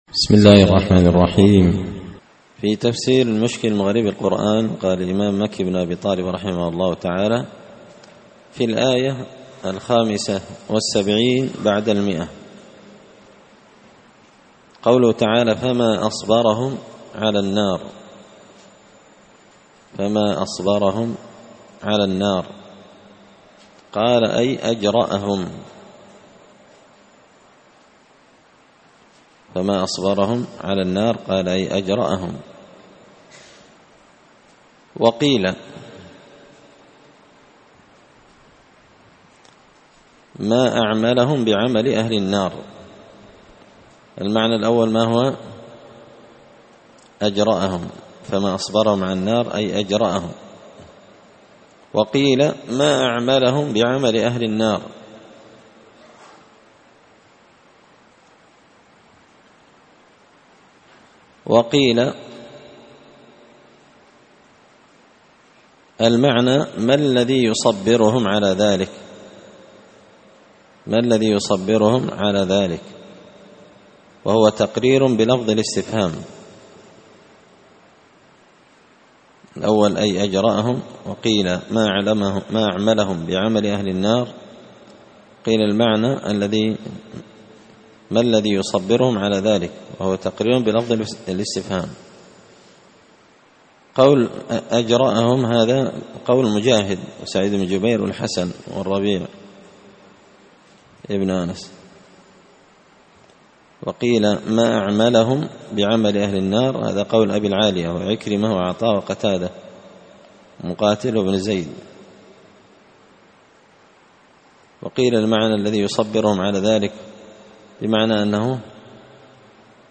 تفسير مشكل غريب القرآن ـ الدرس 30
دار الحديث بمسجد الفرقان ـ قشن ـ المهرة ـ اليمن